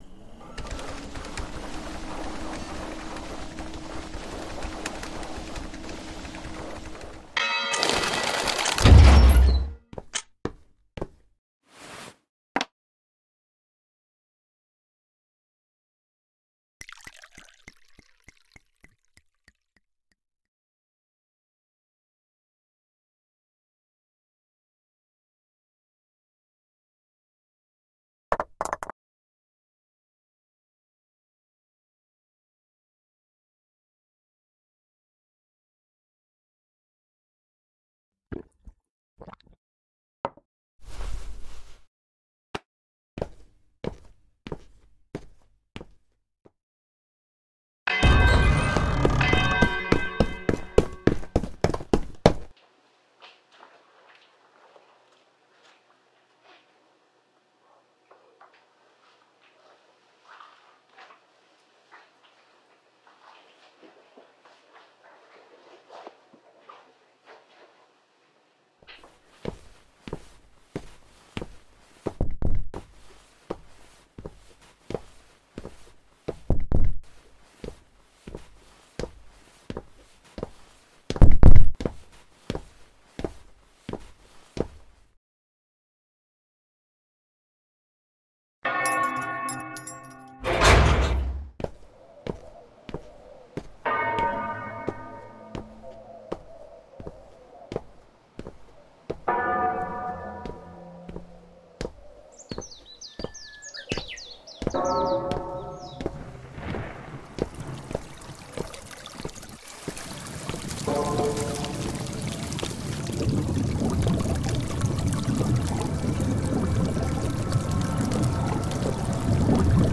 【声劇】Title.null.a